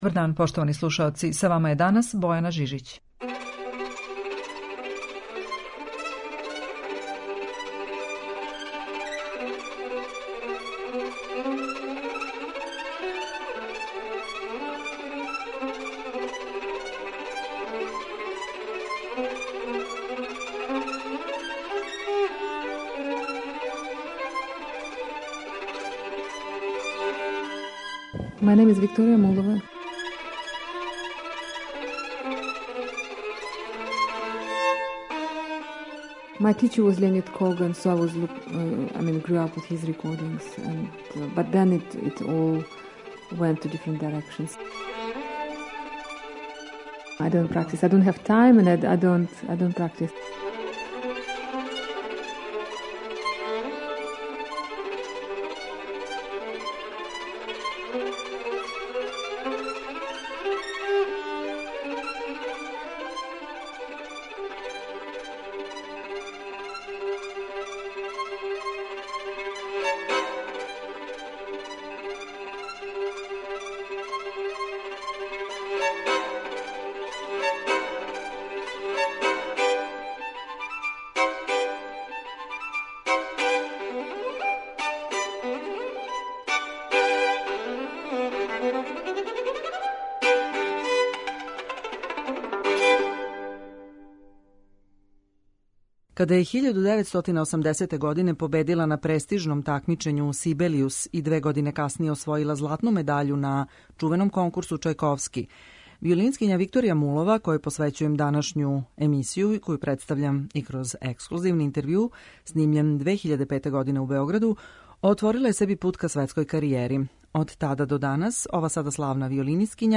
Музички портрет
Данашња емисија посвећена је овој славној руској уметници, коју ћемо представити и као солисту и као камерног музичара.